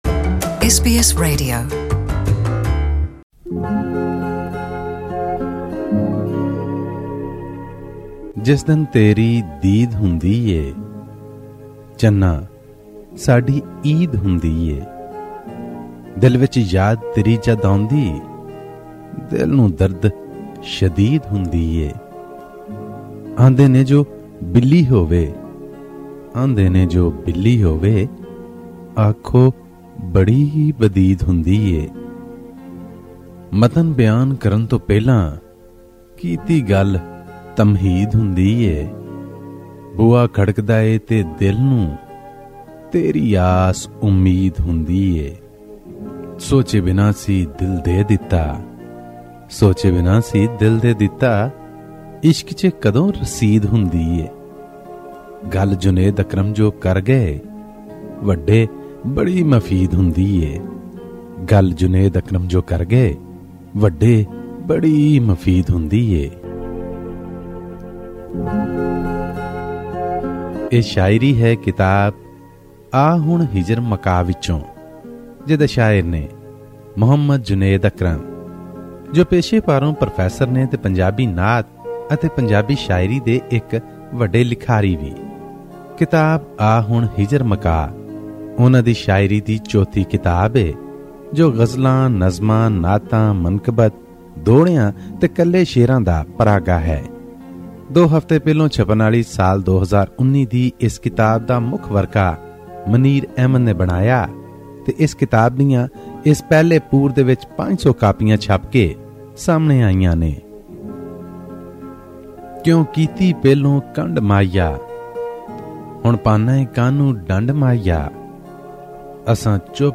Book Review of Pakistani Punjabi poetry by Maqbool Ahmed